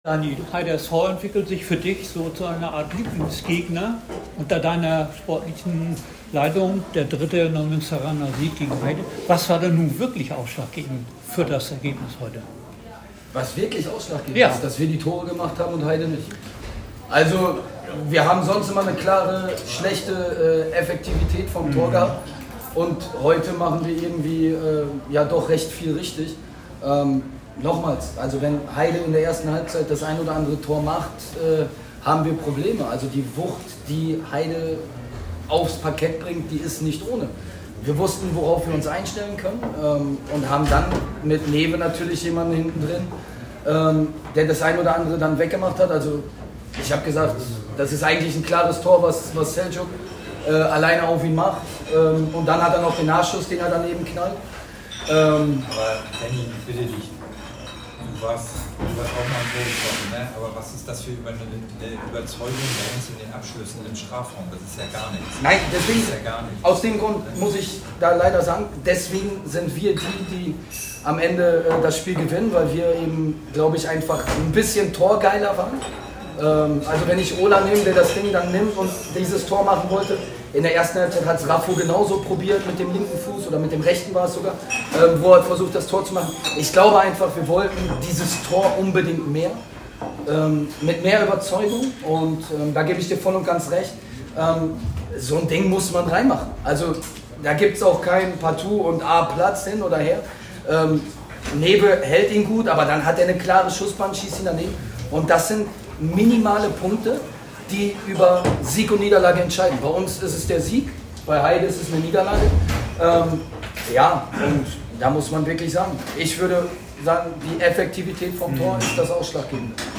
Heider SV - VfR Neumünster PK